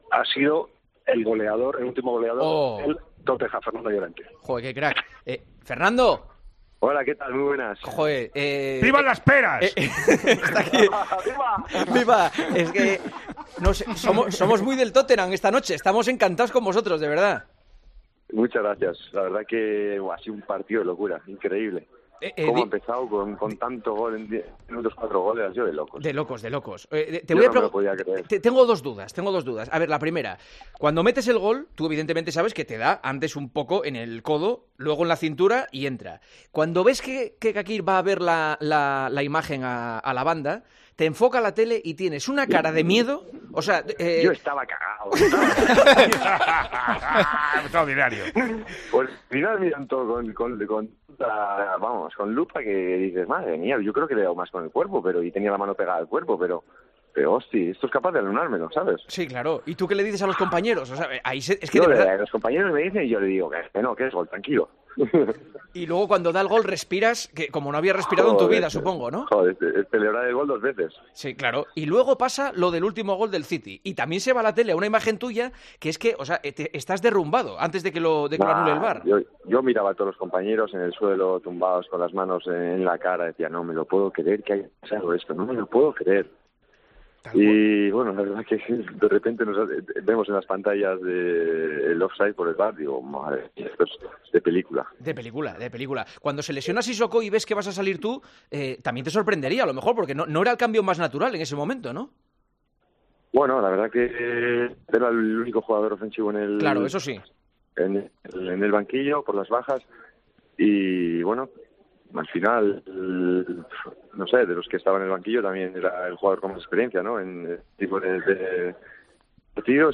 El autor del gol del pase a 'semis' para el Tottenham contó en El Partidazo de COPE cómo vivió un partido "de película. Increíble, no me lo podía creer".